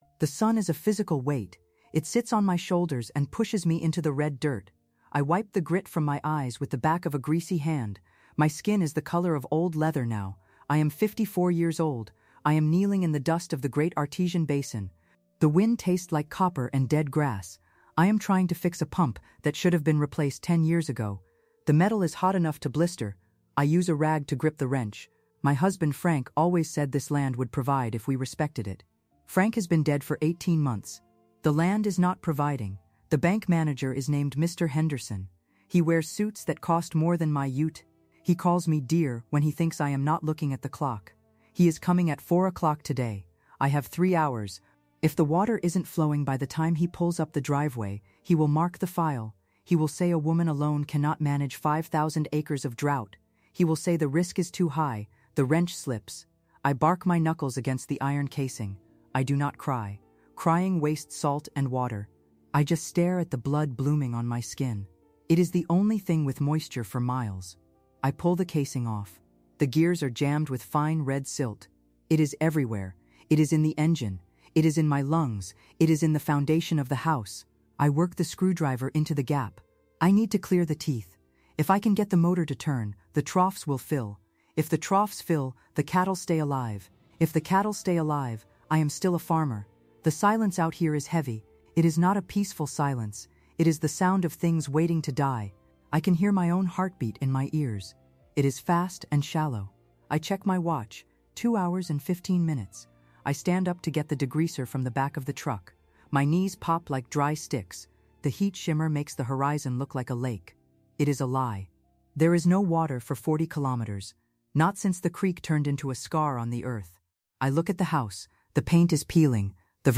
This first-person survival story explores the intersection of grief, grit, and the institutional bias that seeks to strip a woman of her life’s work under the guise of concern.